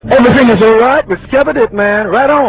jingle_dave_barker.ra